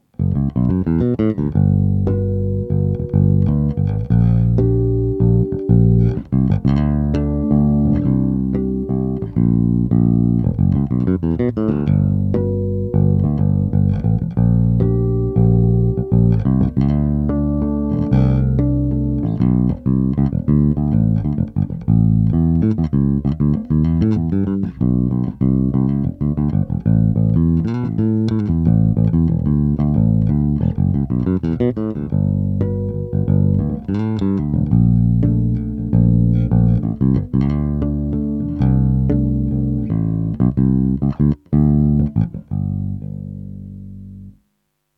Gedenk Cowbell, damit man weiß wo die Eins ist